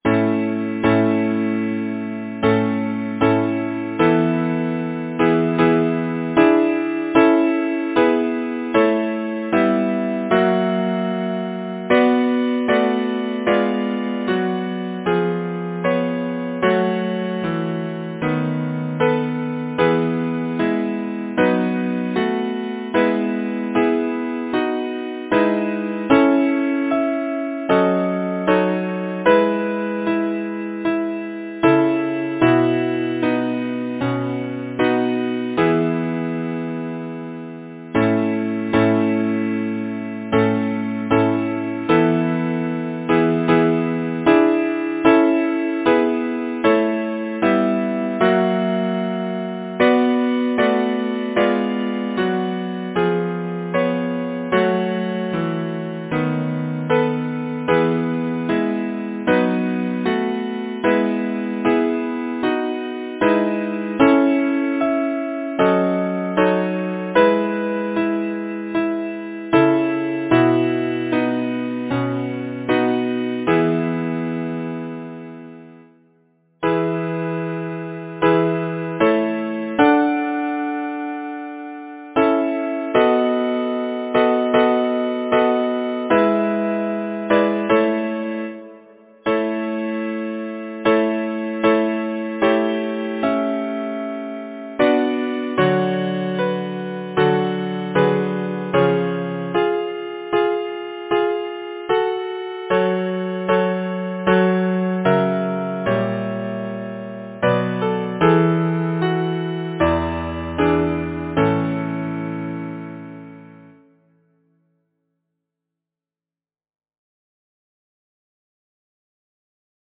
Number of voices: 4vv Voicings: SATB or ATTB Genre: Secular, Partsong
Language: English Instruments: a cappella or Keyboard